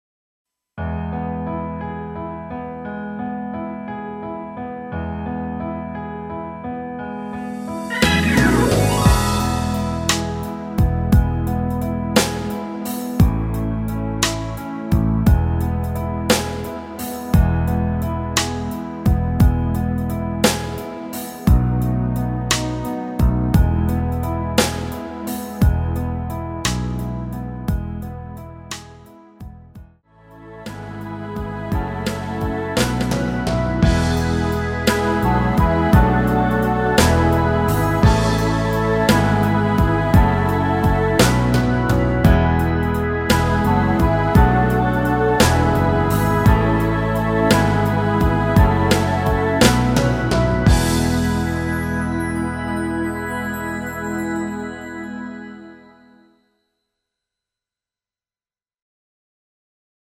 엔딩이 페이드 아웃이라 엔딩을 만들어 놓았습니다. (미리듣기 참조)
앞부분30초, 뒷부분30초씩 편집해서 올려 드리고 있습니다.